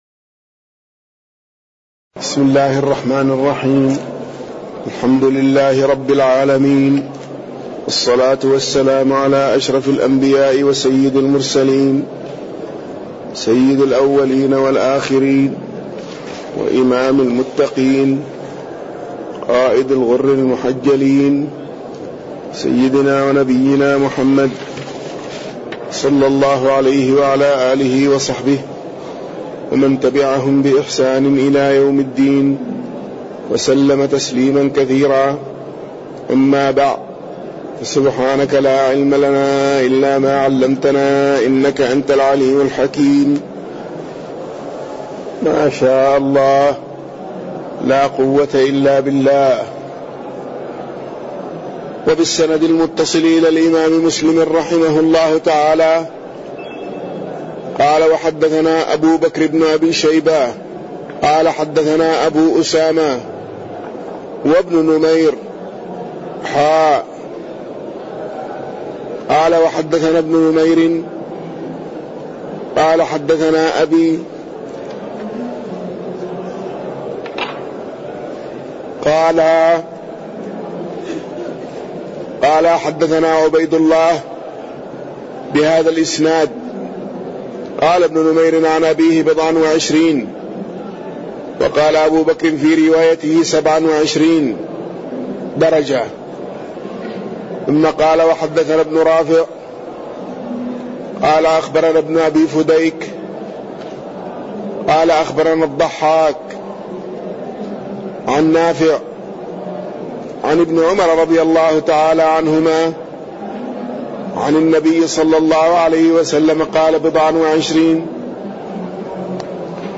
تاريخ النشر ٤ ربيع الأول ١٤٣٠ هـ المكان: المسجد النبوي الشيخ